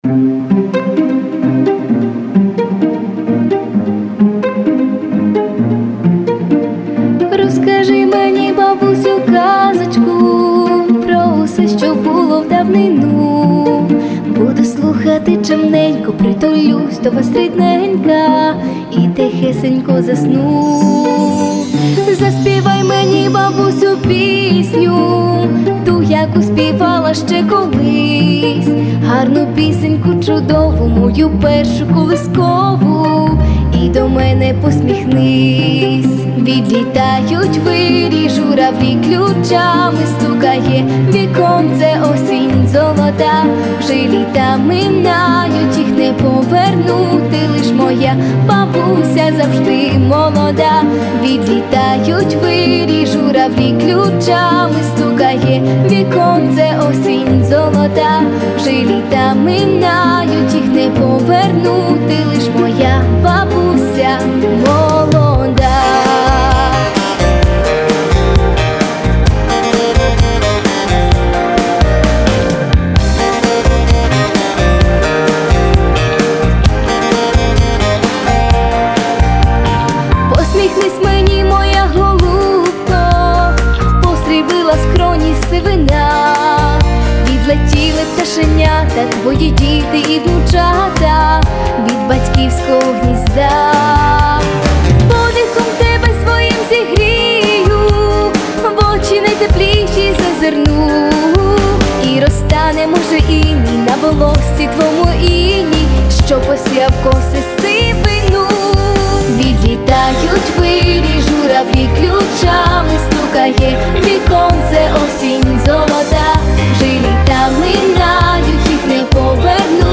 • Жанр: Pop, Folk